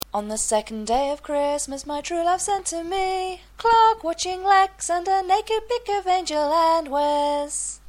More singing!